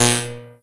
ElectricZap.wav